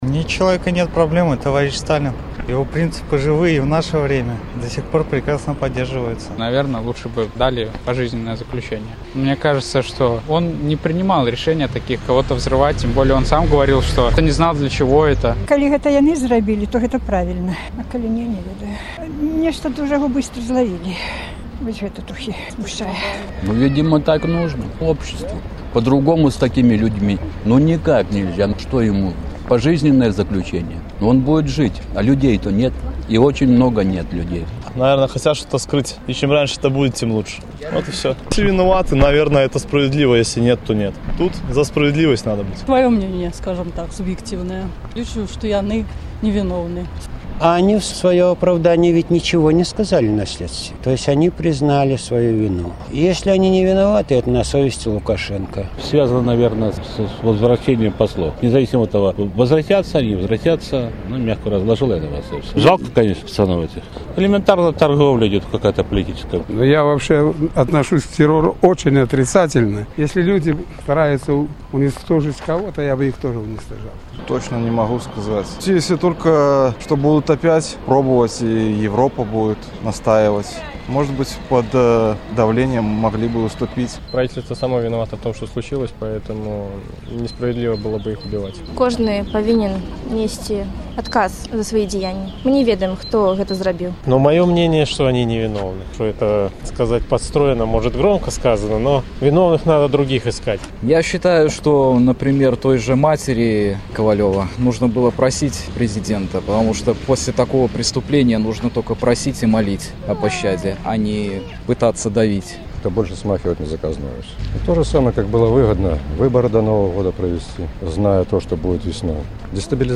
Адказвалі жыхары Магілёва